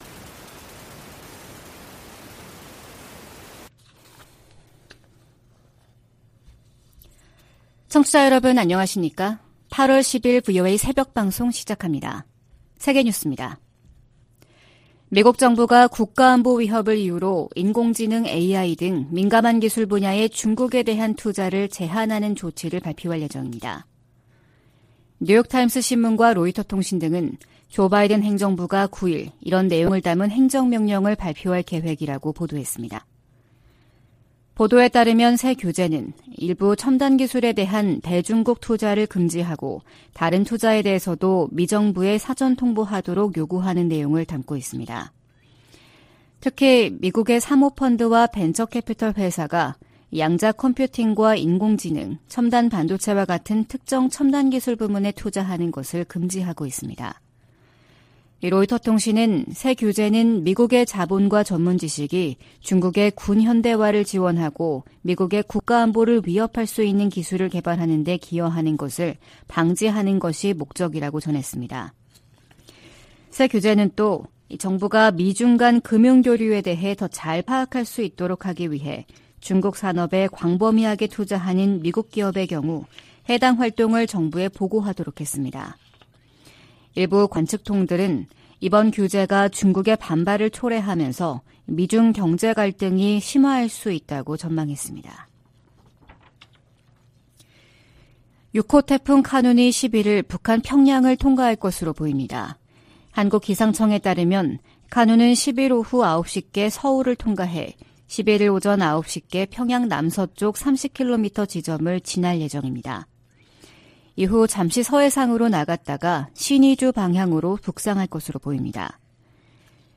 VOA 한국어 '출발 뉴스 쇼’, 2023년 8월10일 방송입니다. 미 국방부는 북한과의 무기 거래는 불법이라며 거기에는 대가가 따를 것이라고 경고했습니다. 중국 중고 선박이 북한으로 판매되는 사례가 잇따르고 있는 가운데 국무부는 기존 대북제재를 계속 이행할 것이라는 입장을 밝혔습니다. 다음 주 미국에서 열리는 미한일 정상회의를 계기로 3국 정상회의가 정례화될 가능성이 높다고 미국 전문가들이 전망했습니다.